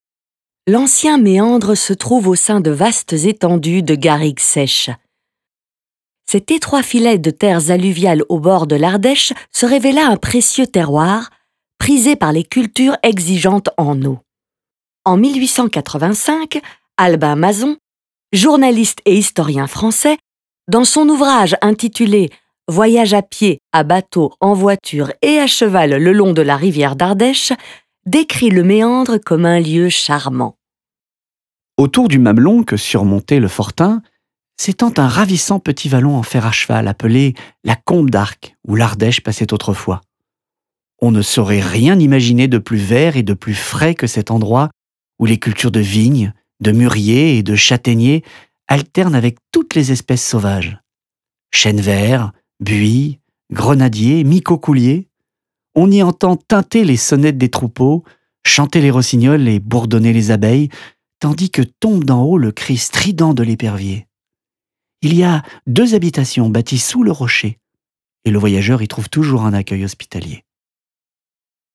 Les contenus audio proposés sont composés d’interviews de scientifiques, de témoignages d’habitants, d’extraits poétiques et historiques.